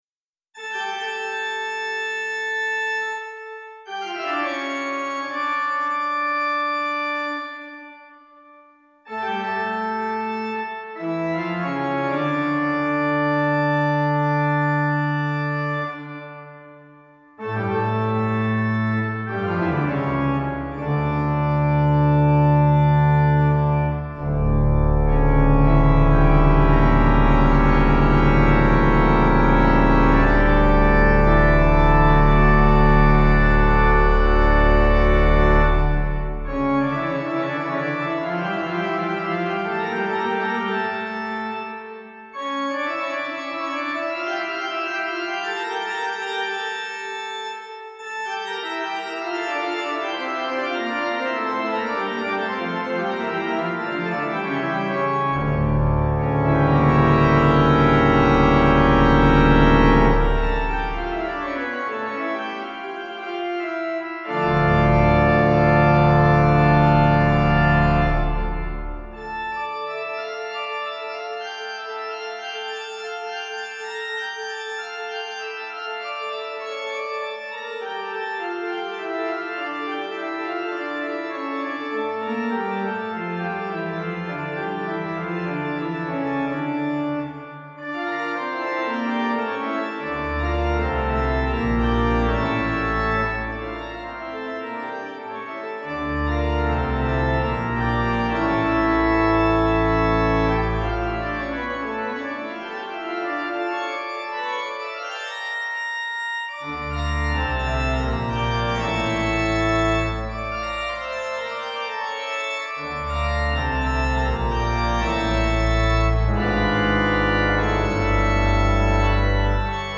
Easy Listening   Dm